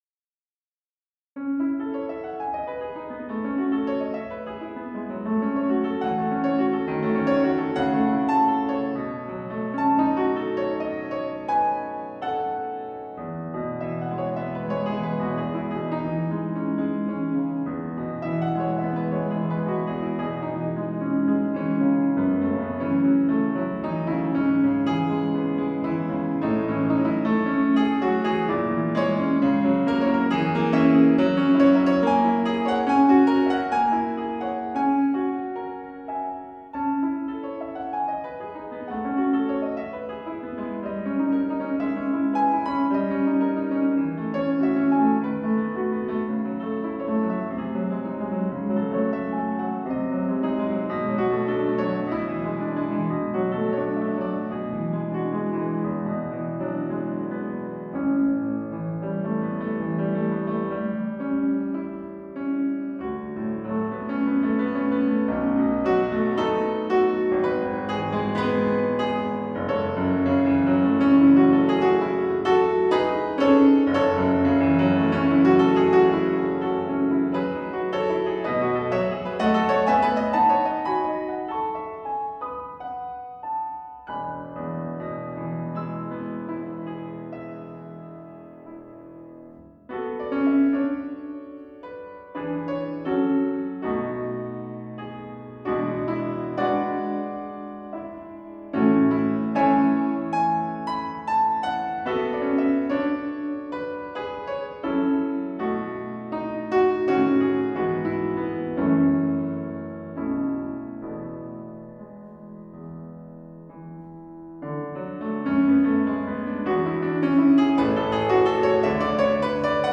同じく＃４つの、ドビュッシーのアラベスク１番、煌びやかさと余韻がしっとり沁みる美しい名曲です。